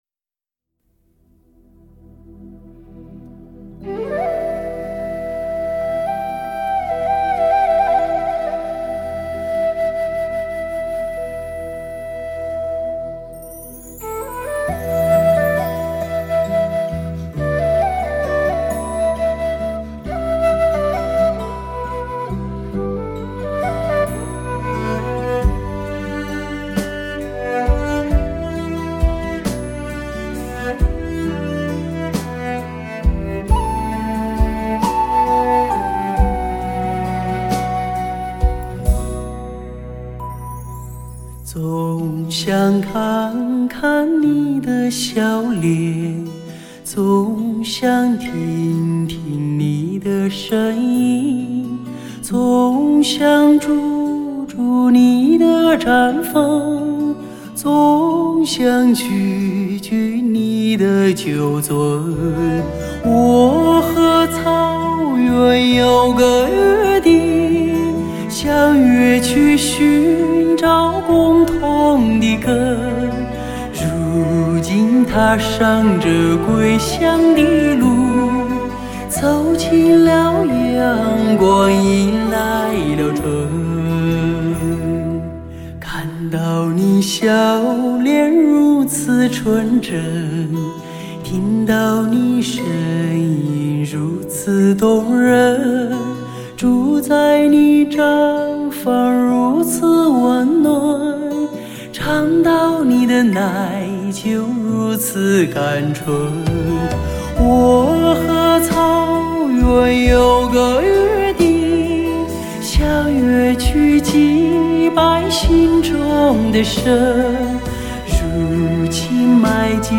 顶级音响测试天碟 国内最佳声乐表现力的发烧唱将
低唱，舒缓透明，融合淡淡的诗意。